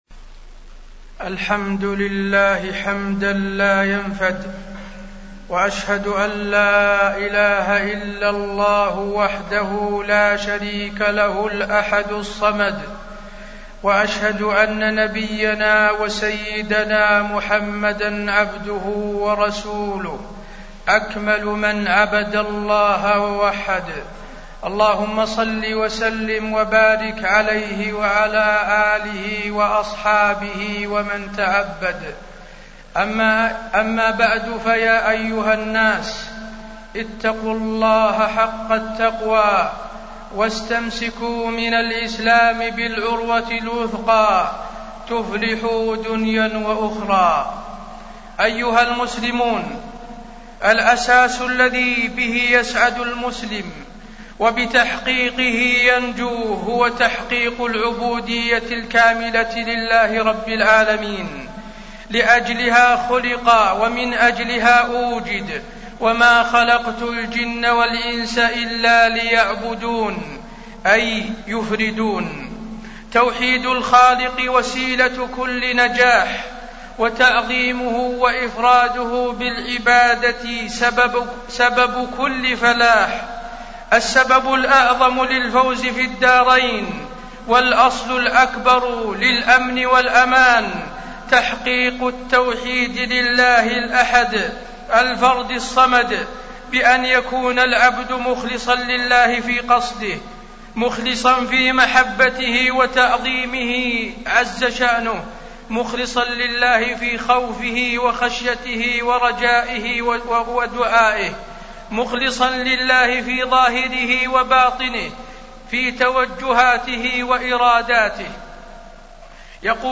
تاريخ النشر ١٣ ذو القعدة ١٤٢٨ هـ المكان: المسجد النبوي الشيخ: فضيلة الشيخ د. حسين بن عبدالعزيز آل الشيخ فضيلة الشيخ د. حسين بن عبدالعزيز آل الشيخ توحيد الله عز وجل